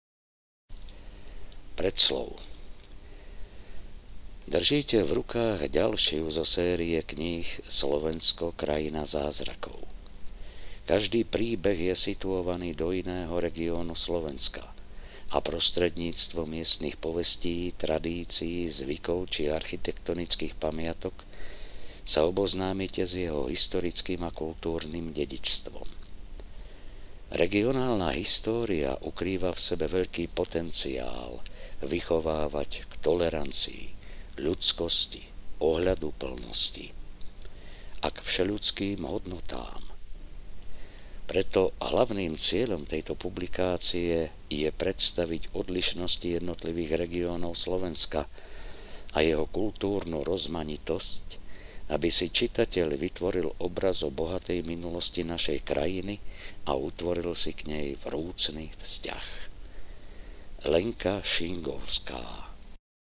Prázdniny s deduškom 2 audiokniha
Ukázka z knihy